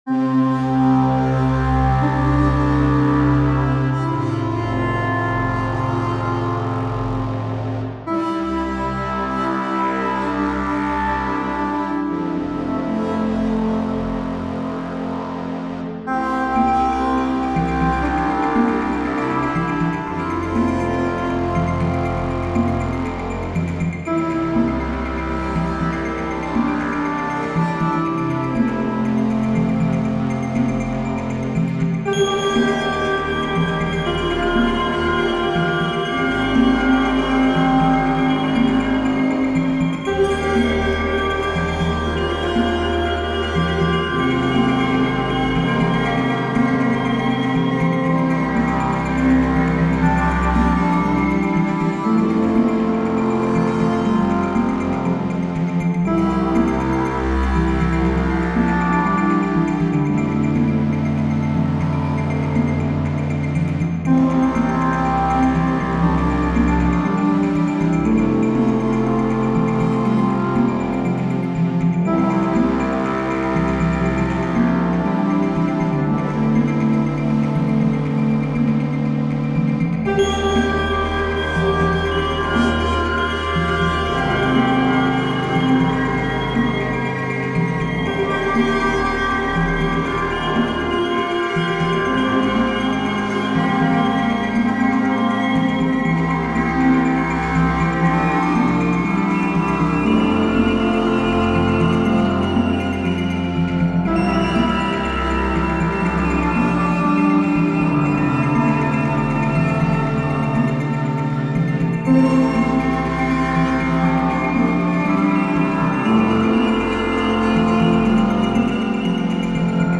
Serene oscillators twinkle on west coast mornings.